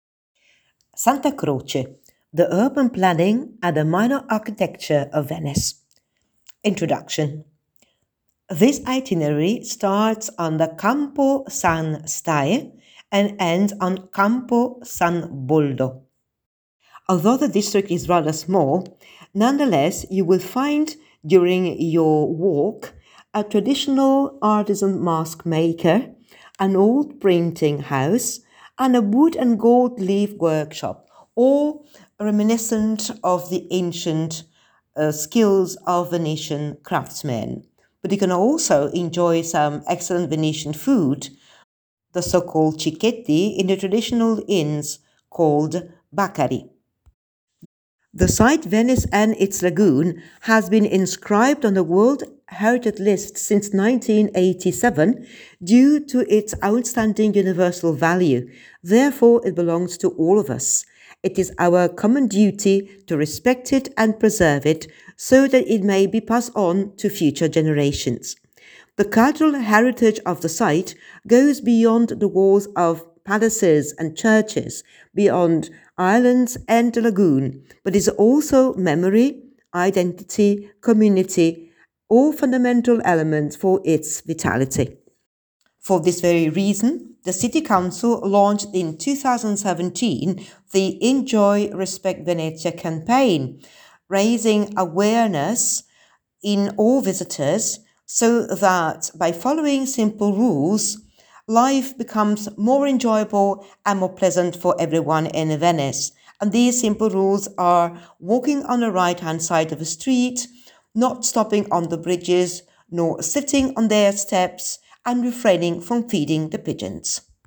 Listen to the audio tour